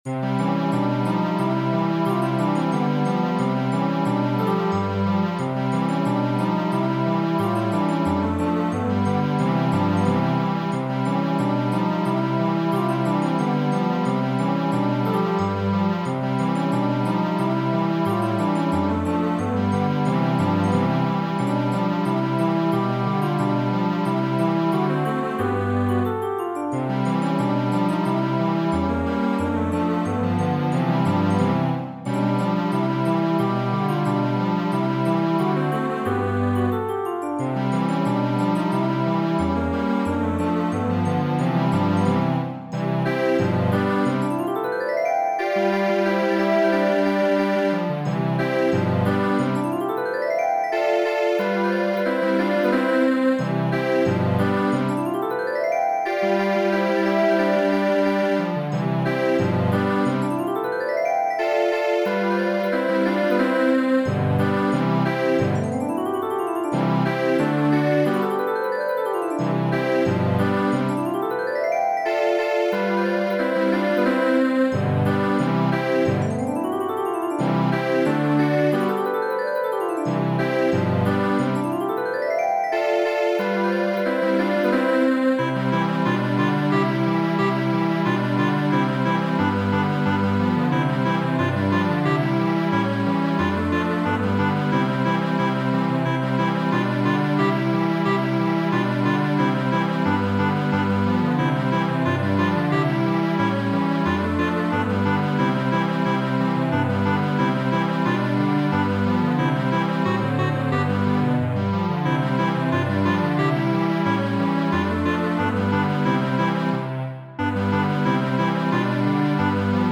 Temo variita, verkita de Fernando Sor por gitaro, kaj orĥestrigita de mi mem.